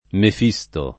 Mefistofele [mefiSt0fele] pers. m. mit.